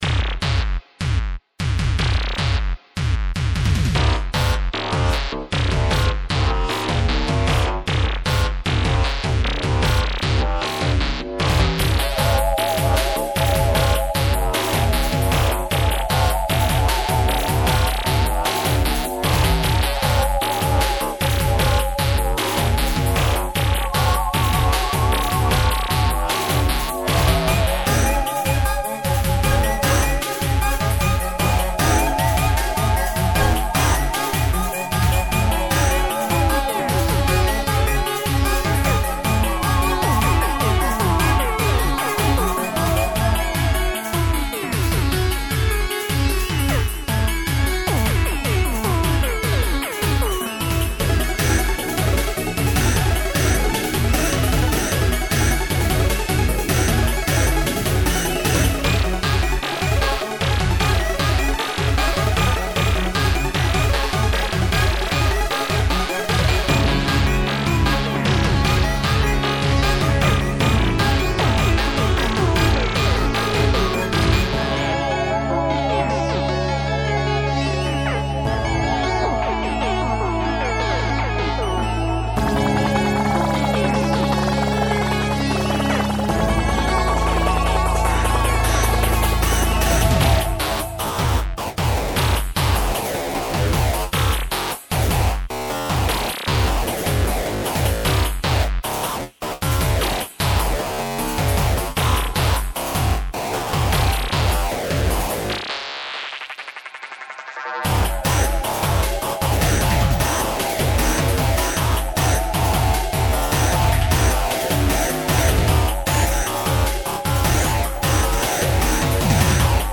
One of a few bits of music I made exclusively with a software synthesizer, in this case Seer Systems' Reality.
The piece is positively riddled with controller data.
Also done in 5/4 time. Once in a while I'd pick a less common time signature to get away from the typical EBM 4/4 formula, just for a little variety.